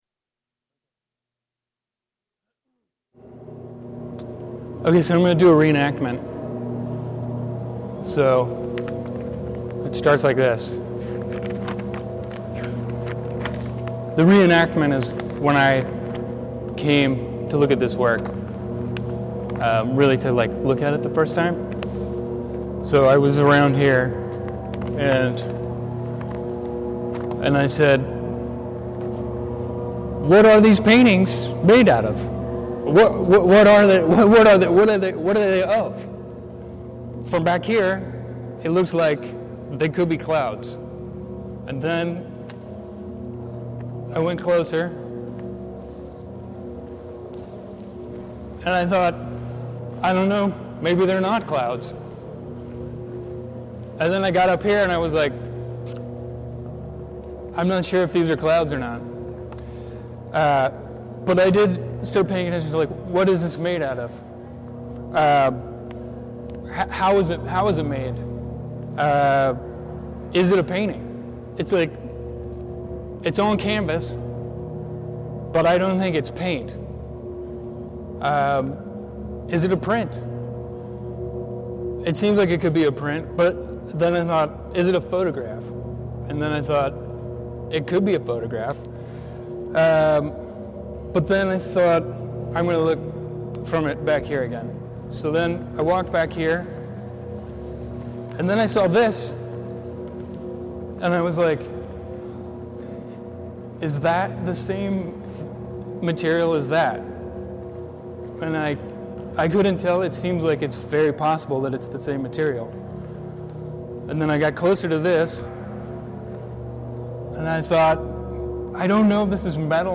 In conjunction with Fifty Years of Bay Area Art: The SECA Awards, we’ve restyled our weekly in-gallery talks with a superb lineup of past SECA Art awardees. Each Thursday at 6:30 p.m. an artist talks about something on view.